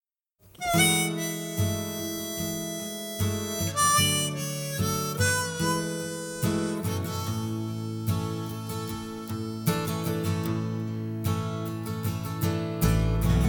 WIP - working on the strum timing
Capo 2